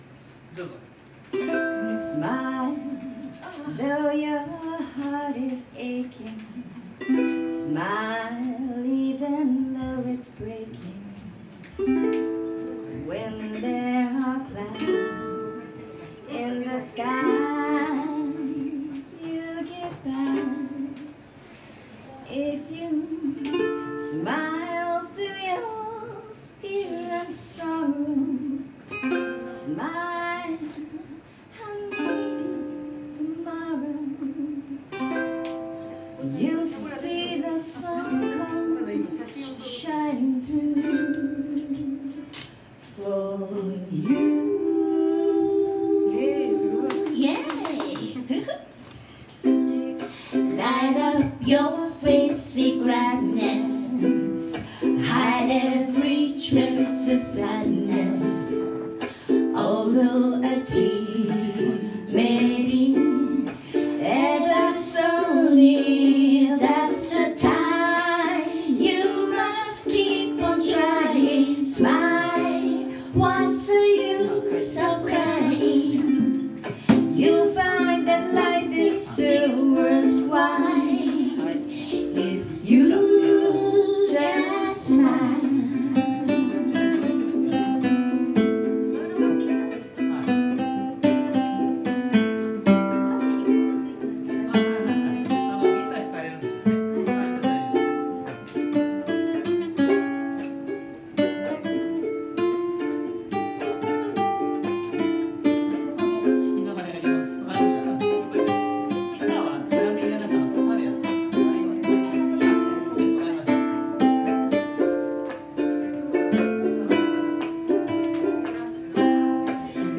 I love music, singing and playing my ukulele!